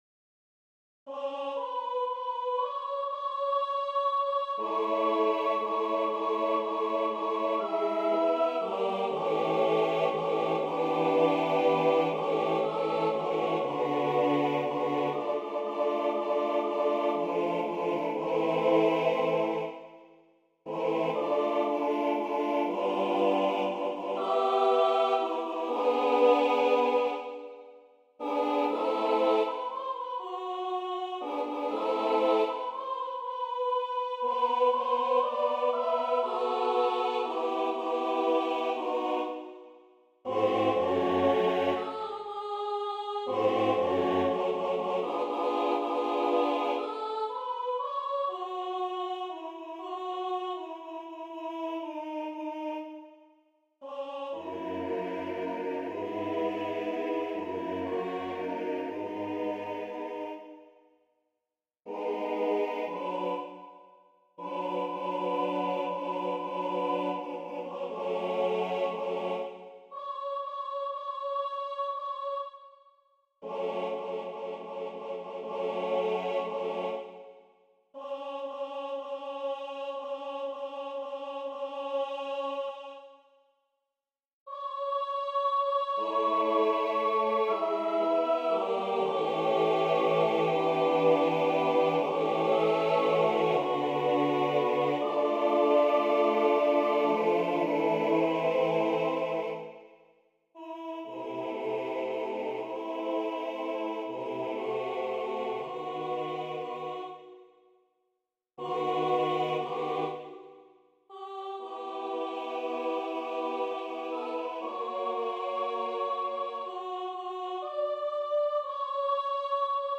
Number of voices: 4vv Voicing: SATB Genre: Sacred, Partsong
Language: French Instruments: A cappella